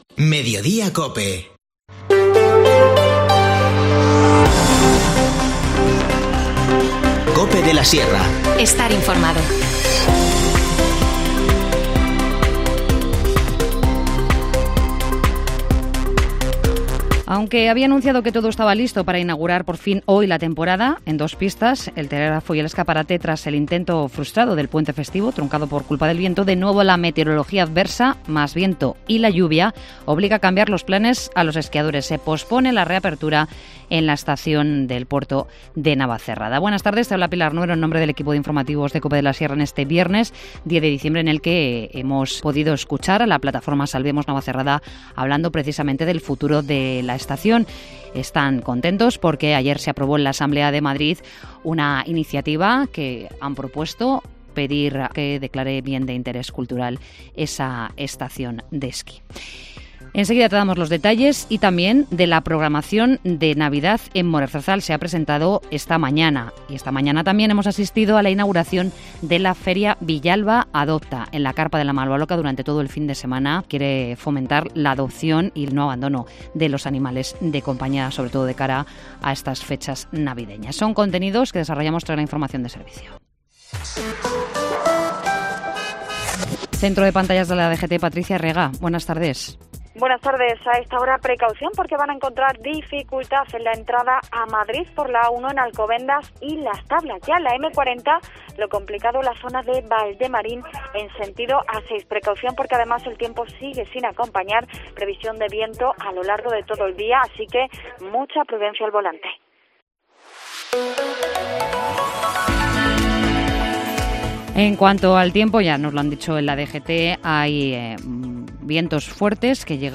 Informativo Mediodía 10 diciembre